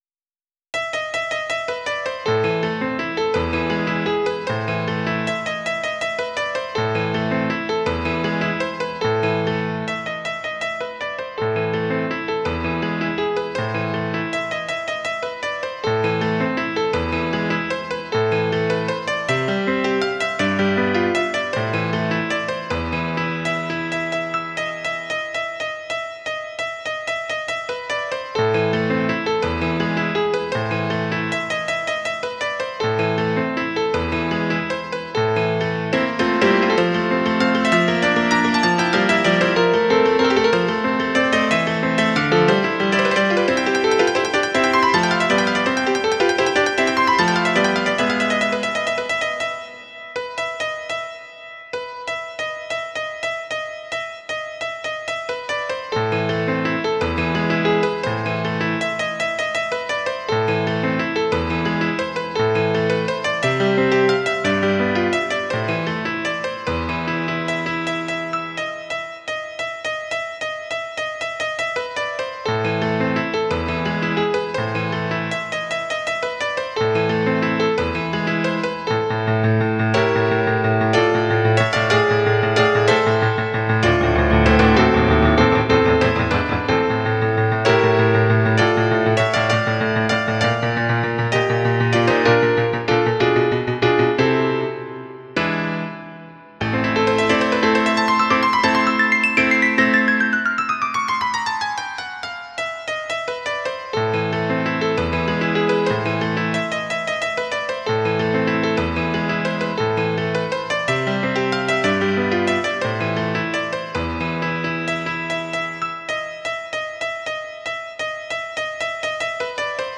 piano
bagatela
menor
romanticismo